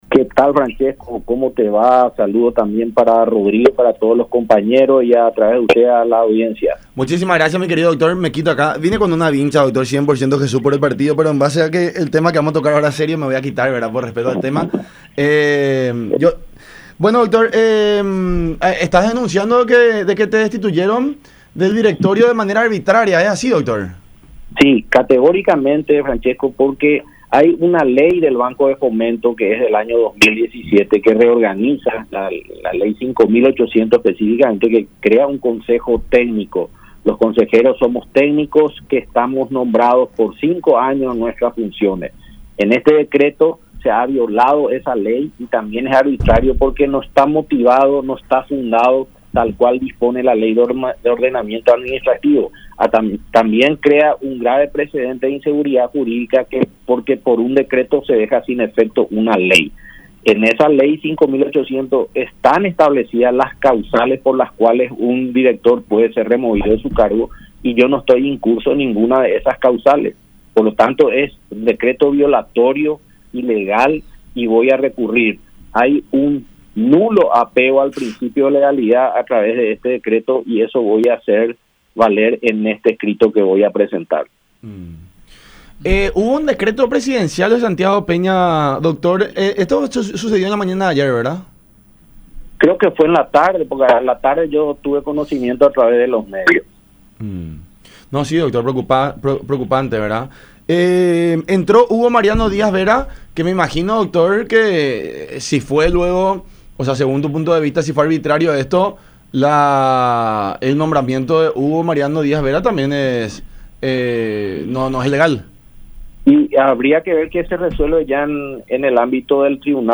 “Hay una Ley del Banco de Fomento que crea un Consejo Técnico. Los consejeros somos técnicos que somos nombrados por cinco años en nuestras funciones, eso se ha violado”, dijo Carlos Arregui en el programa “La Unión Hace La Fuerza”, por Unión TV y radio La Unión.